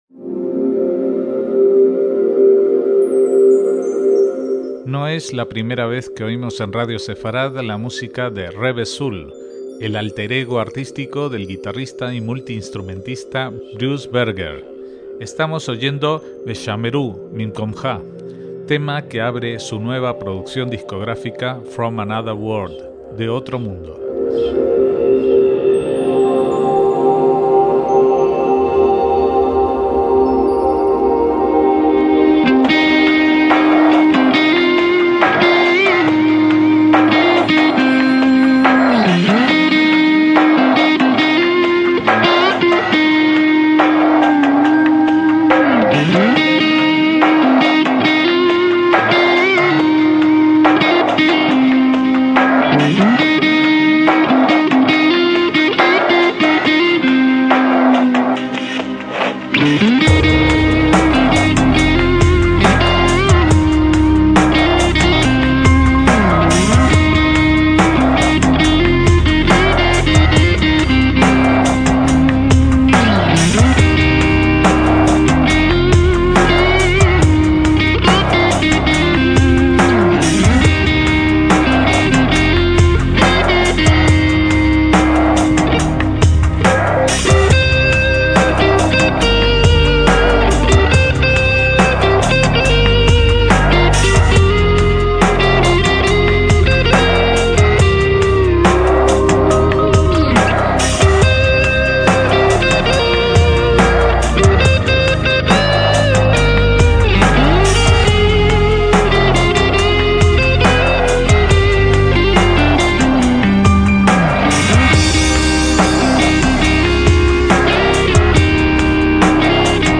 cantautor, multiinstrumentista y productor musical
una compilación de versiones instrumentales